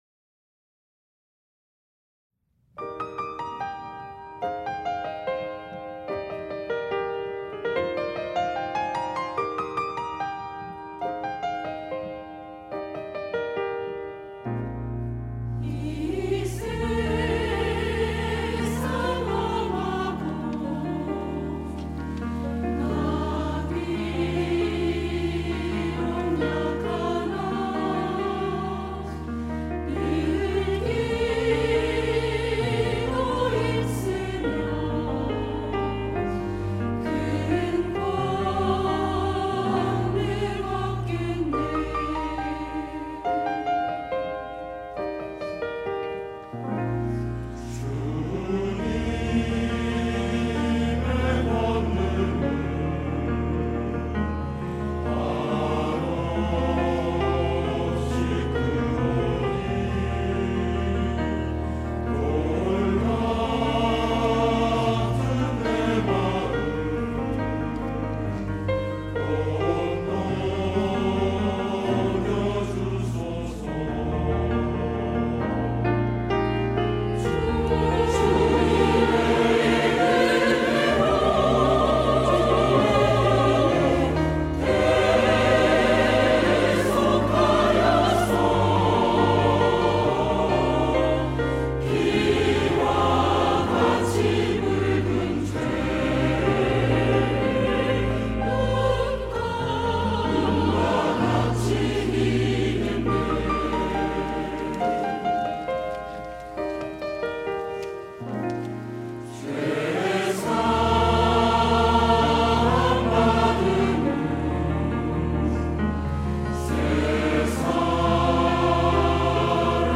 시온(주일1부) - 이 세상 험하고
찬양대